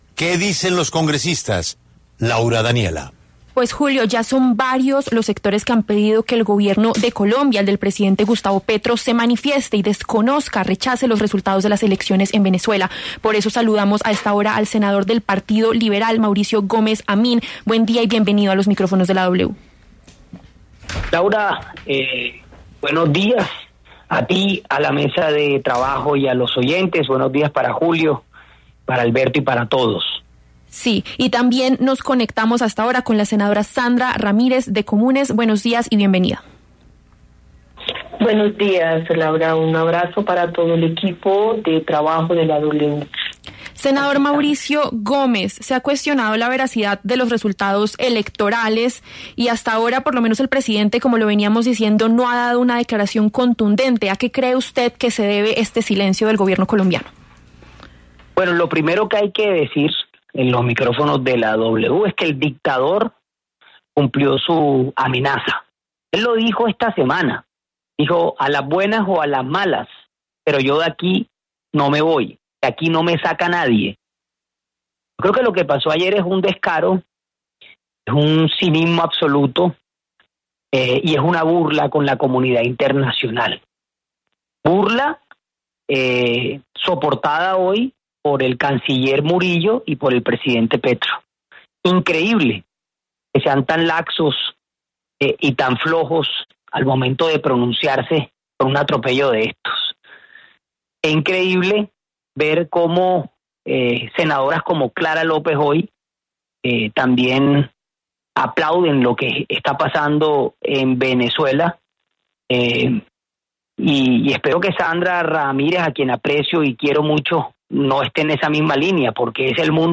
En los micrófonos de La W, debatieron los senadores Mauricio Gómez Amín, del Partido Liberal, y Sandra Ramírez, de Comunes, quien ha mantenido una posición en defensa del régimen de Maduro, al igual que su partido, fruto del acuerdo de paz con las extintas Farc.